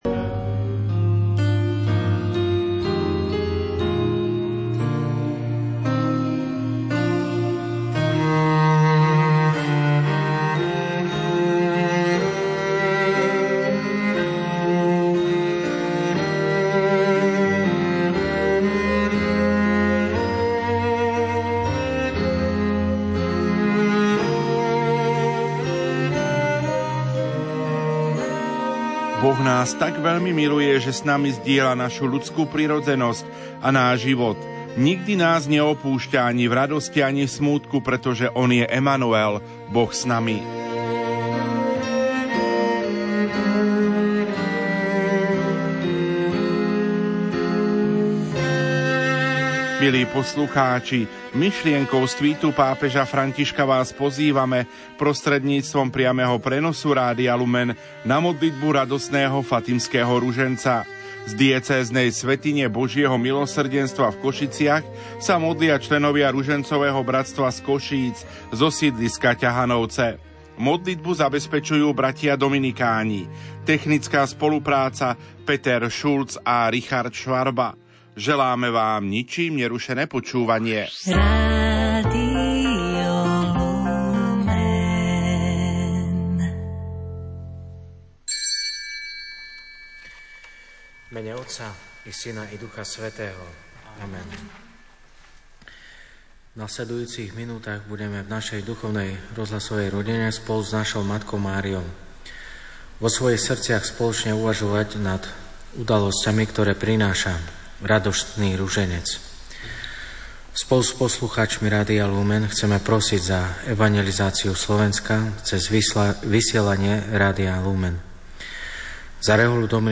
modlitba zo svätyne Božieho milosrdenstva Košice-KVP